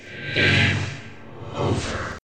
game_over.ogg